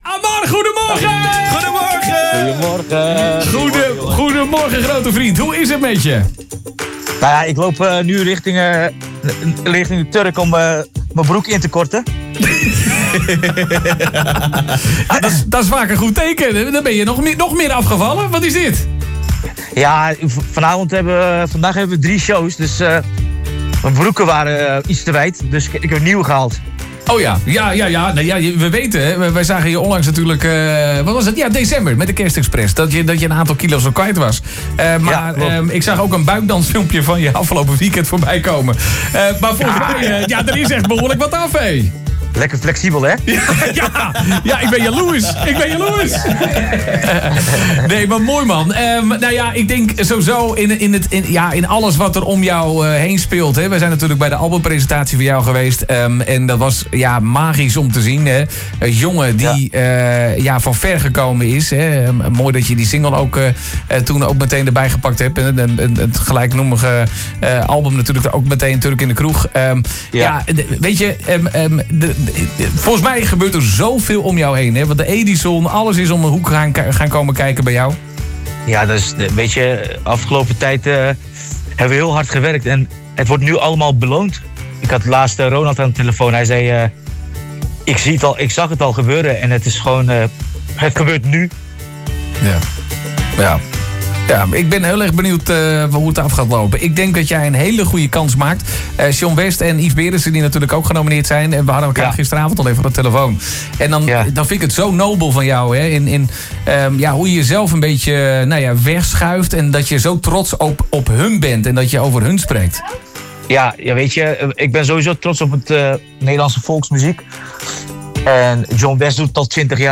We belden hem met de verrassing dat hij drager is van de Radio Continu Schijf deze aankomende week.